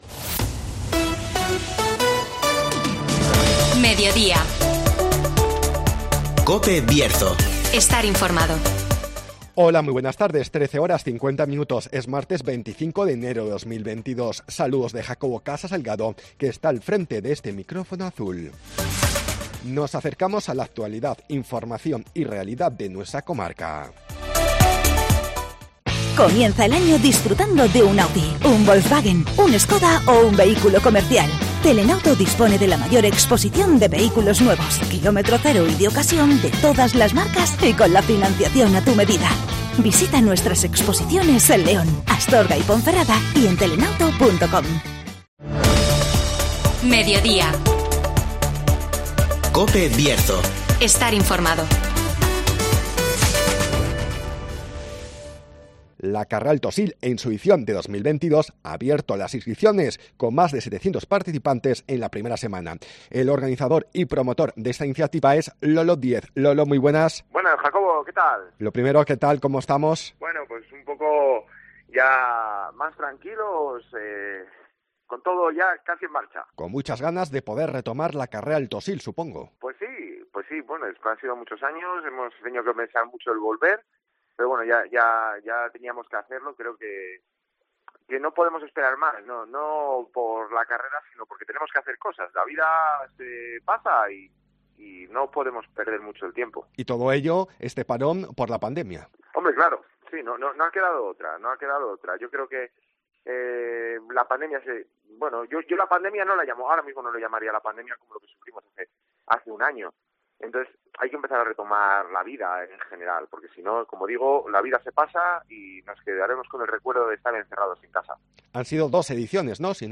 La Carrera Alto Sil 2022 abre las inscripciones con más de 700 participantes en la primera semana (Entrevista